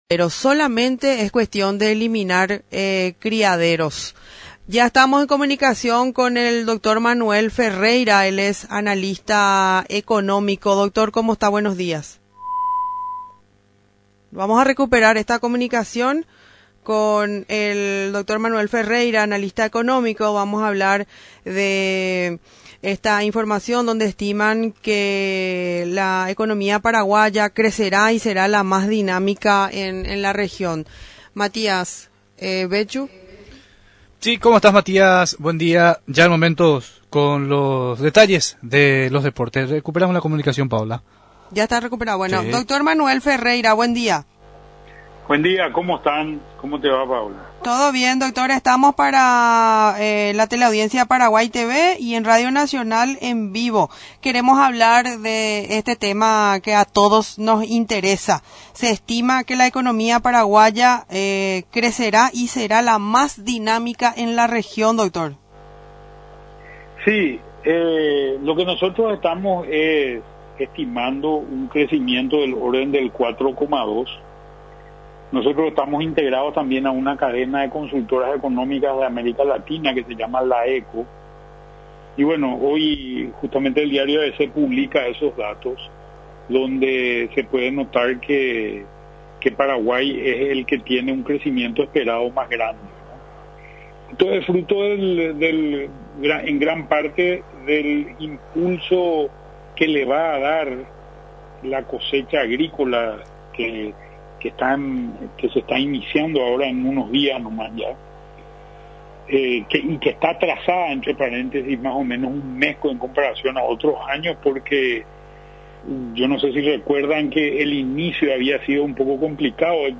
en comunicación con la Radio Nacional del Paraguay.